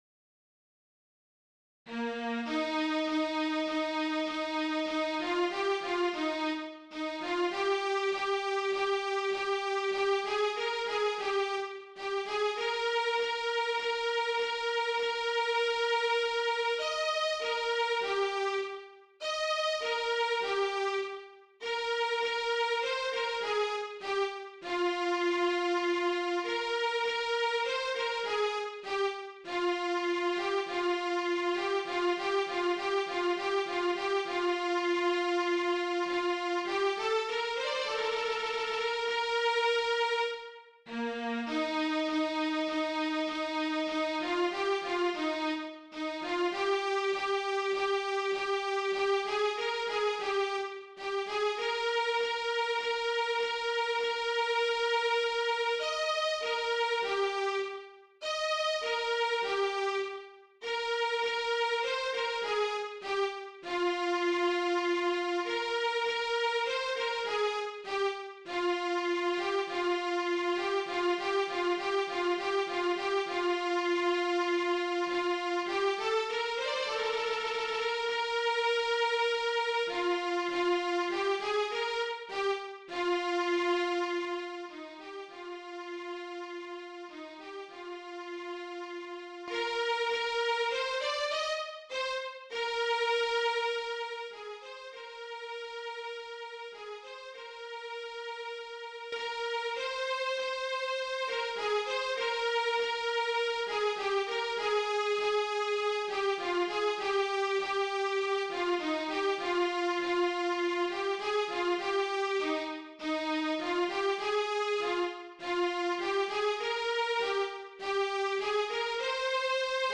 DIGITAL SHEET MUSIC - VIOLA SOLO